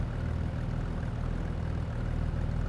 idle.wav